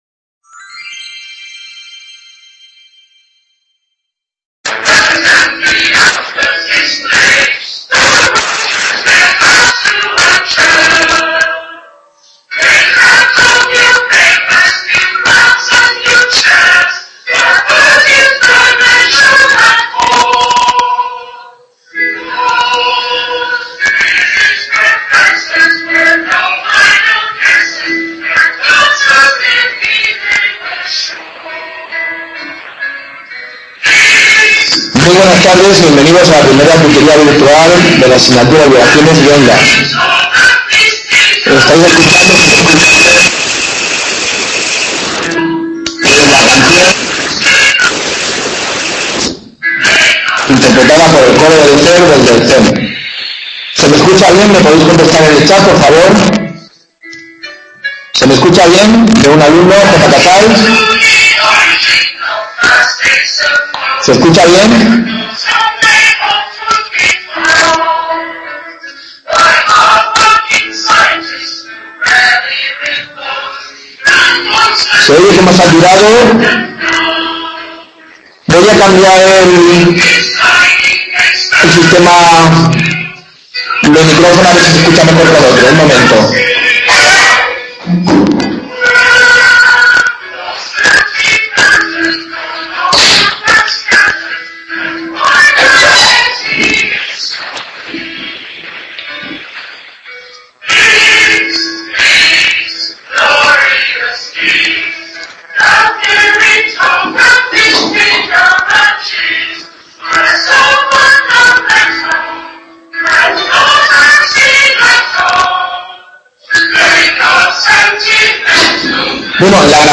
Tutoría virtual 1, parte 1/2, relativa al tema 1 de la asignatura del grado en Física, Vibraciones y Ondas. Cinemática del m.a.s, y superposición de vibraciones.